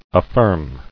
[af·firm]